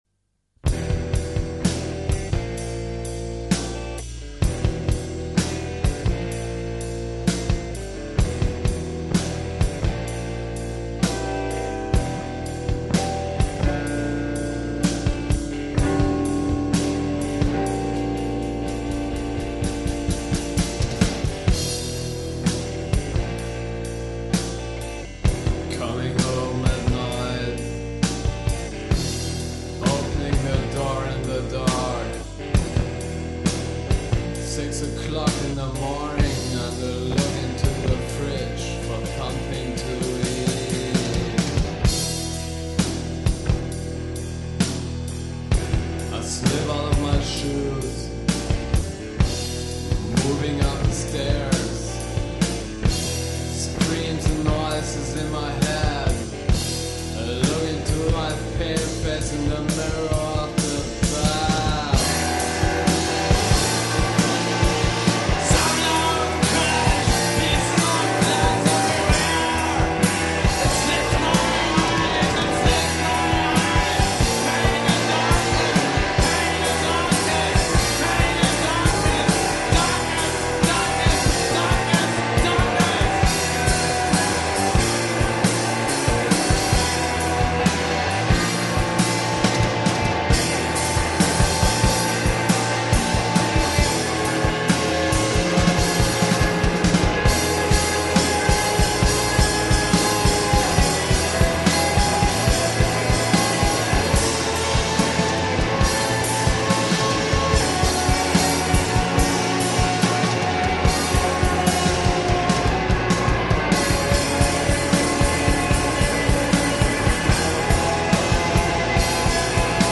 Gitarren Rock
voc, git, banjo, whistle, special noise effects
bass
drums